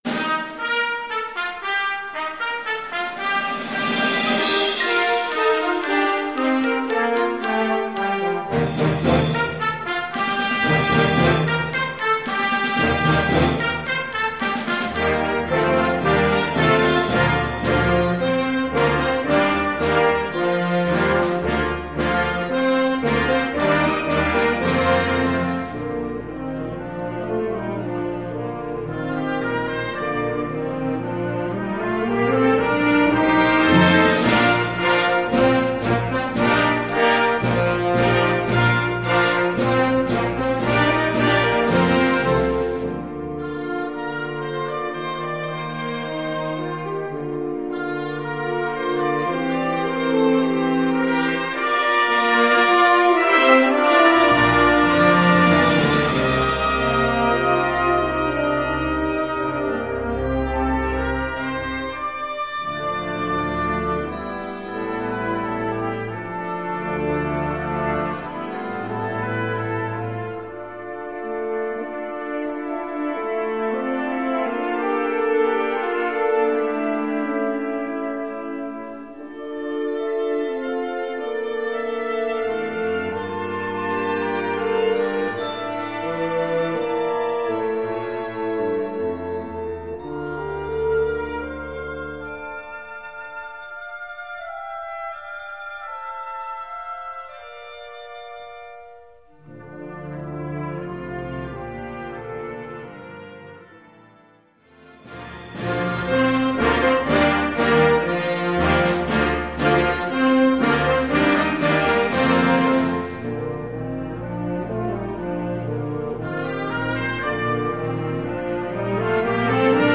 3:20 Minuten Besetzung: Blasorchester Zu hören auf